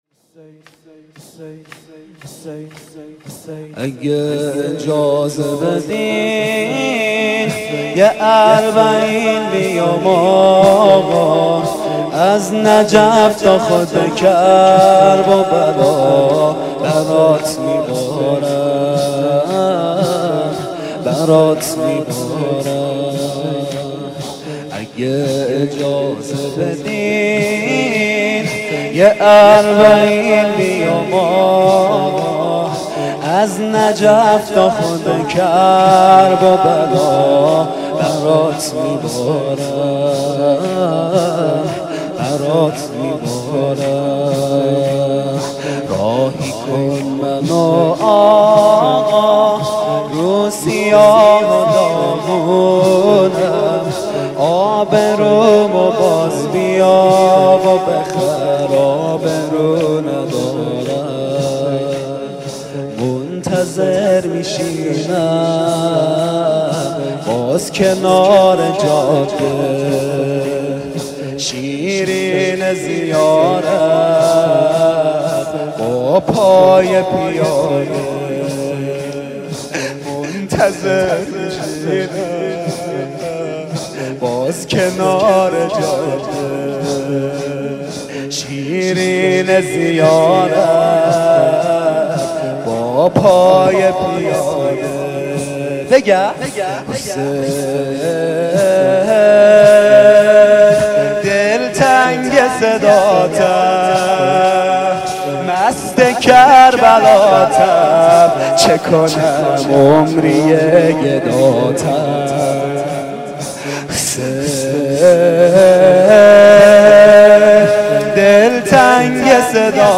نوحه.mp3